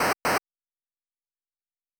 Stairs.wav